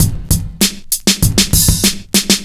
• 98 Bpm Breakbeat Sample E Key.wav
Free drum groove - kick tuned to the E note. Loudest frequency: 4456Hz
98-bpm-breakbeat-sample-e-key-dpm.wav